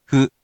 We’re going to show you the character, then you you can click the play button to hear QUIZBO™ sound it out for you.
In romaji, 「ふ」 is transliterated as 「fu」and 「hu」which sounds sort of like the foo in「fool